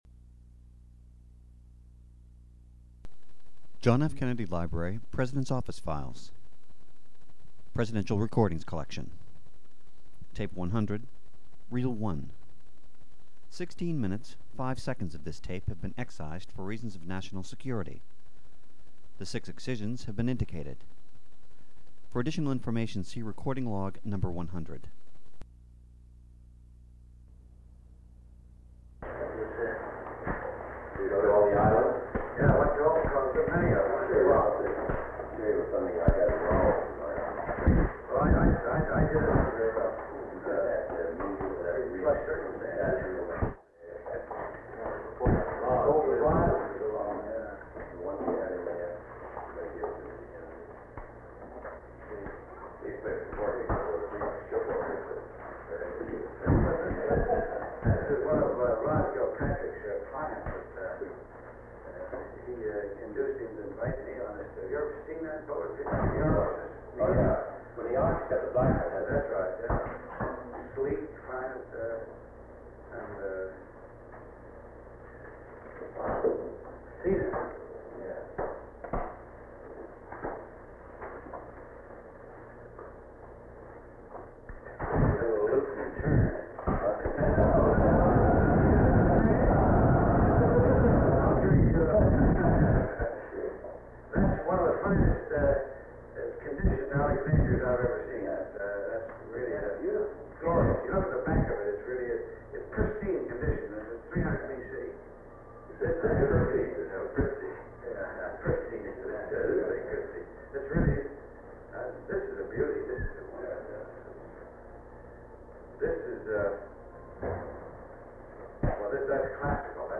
Sound recording of a meeting held on July 23, 1963, between President John F. Kennedy, Under Secretary of State George Ball, John McCloy, Assistant Secretary of State Phillips Talbot, United States Ambassador to the United Arab Republic (UAR) John Badeau, Director of the Central Intelligence Agency (CIA) John McCone, Paul Nitze, Special Assistant to the President for National Security Affairs McGeorge Bundy, member of the National Security Council Robert W. Komer, and Hermann Eilts. They discuss a possible Near East arms limitation agreement, and specifically relations between the UAR and Israel. Five segments of the recording totaling 15 minutes and 27 seconds have been removed in accordance with Section 3.4 (b) (1), (3) of Executive Order 12958.